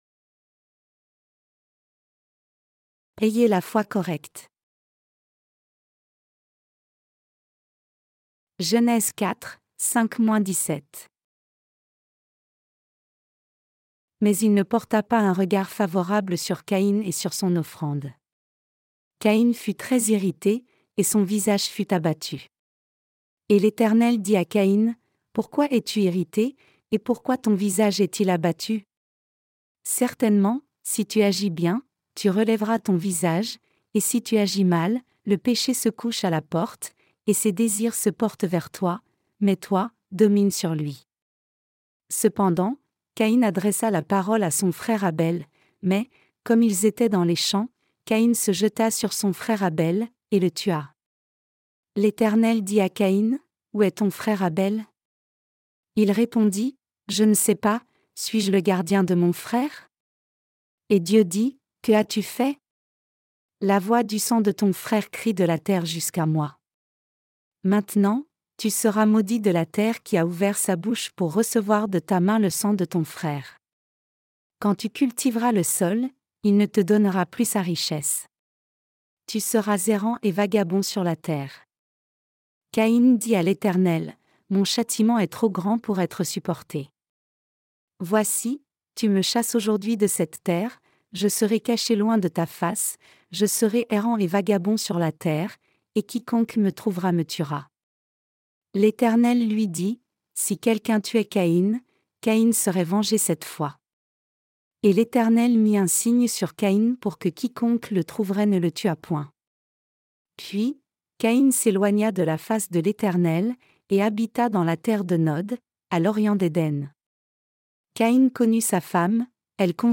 Sermons sur la Genèse (V) - LA DIFFERENCE ENTRE LA FOI D’ABEL ET LA FOI DE CAÏN 5.